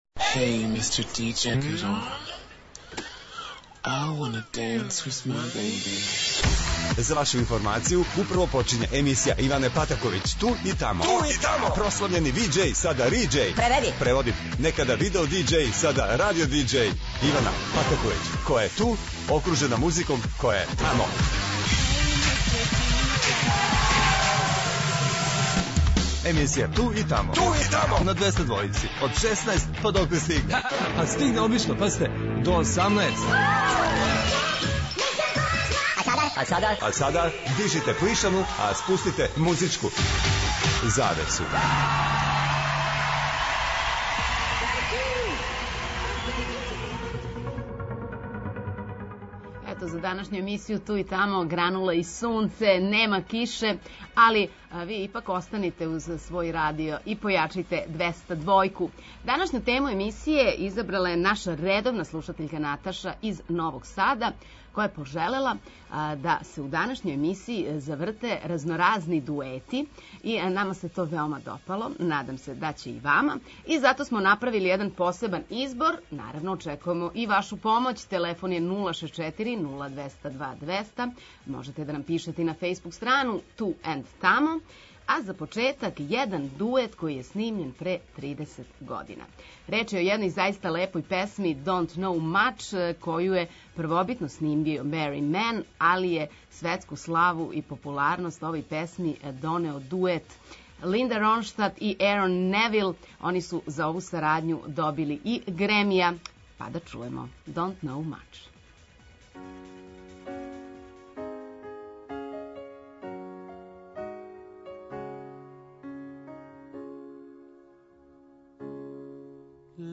И зато се спремите за два сата супер супер дуета којих има и на страној и на домаћој сцени.